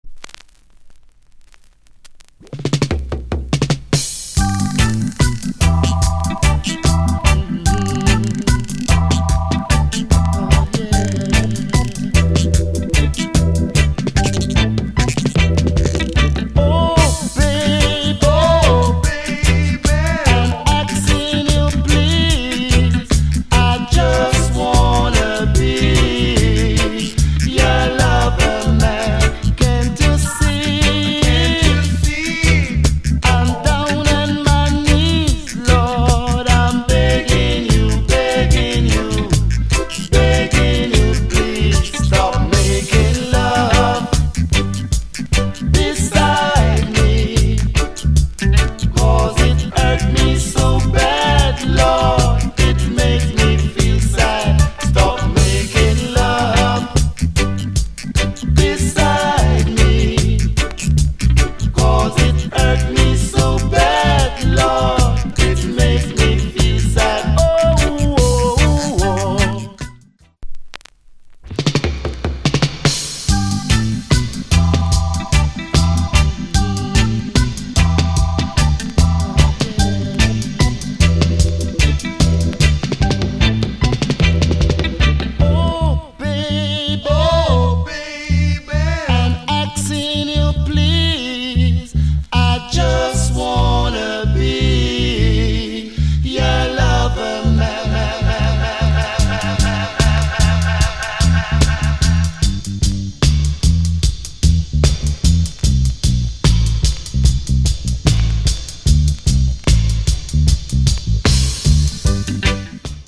* Reggae Cut